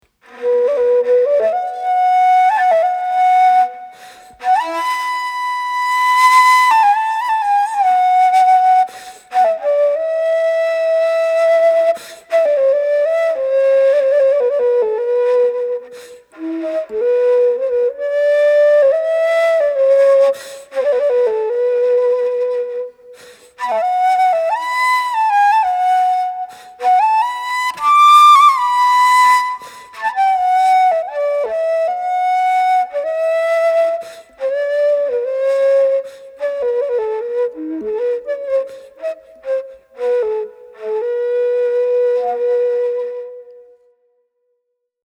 El Ney (o la Nay) és una flauta de canya de set forats. És un instrument de vent que evoca profunds sentiments.
Taksim-Ney.mp3